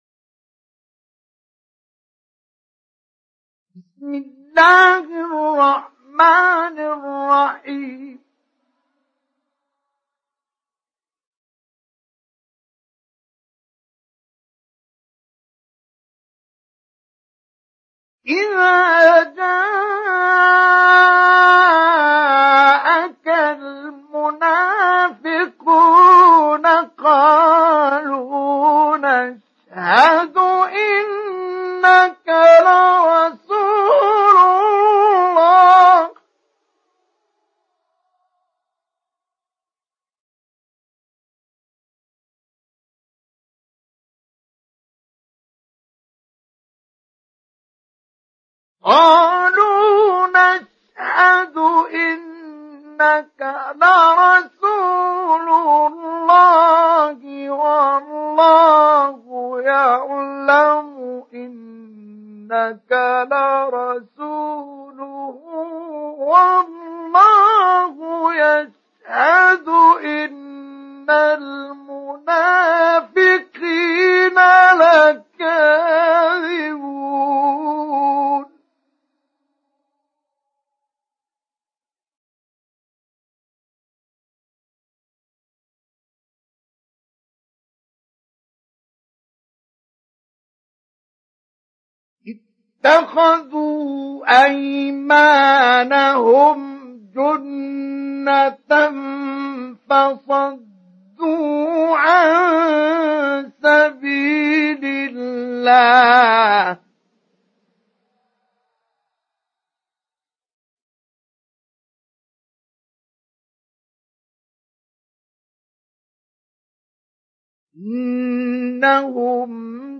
Surah Al Munafiqun Download mp3 Mustafa Ismail Mujawwad Riwayat Hafs from Asim, Download Quran and listen mp3 full direct links
Download Surah Al Munafiqun Mustafa Ismail Mujawwad